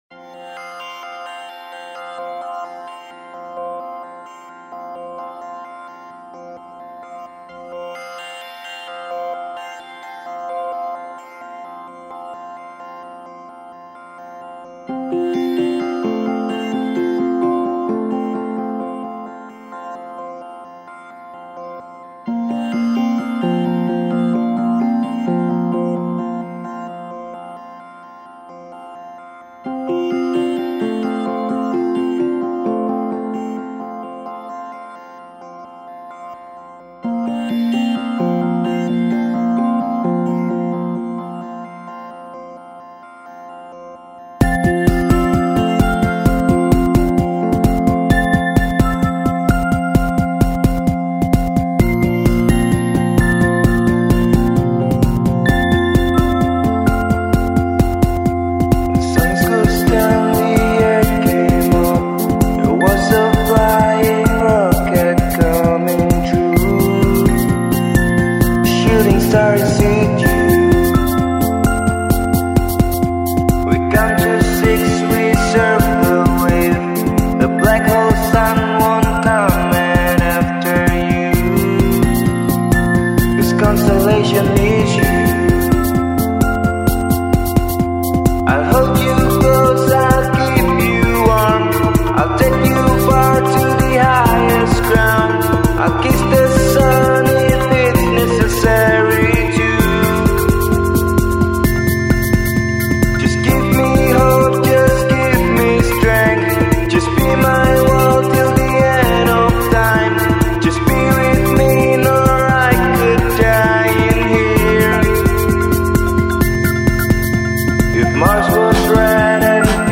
Yogyakarta Electronic